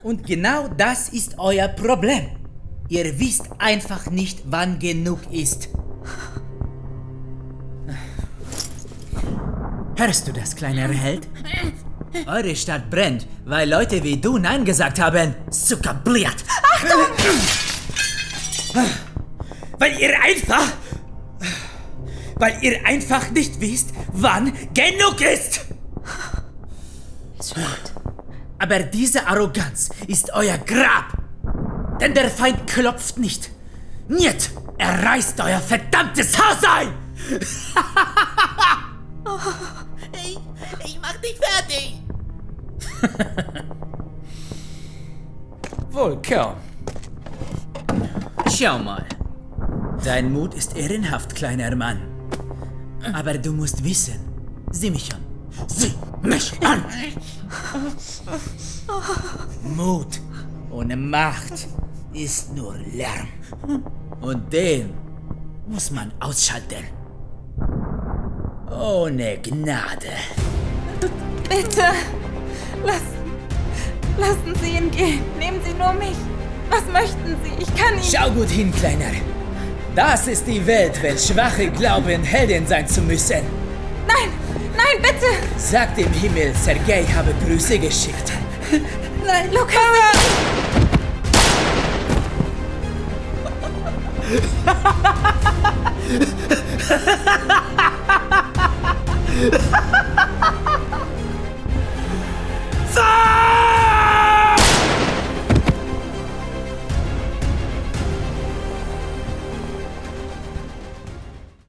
Hörspiel, Bedrohlich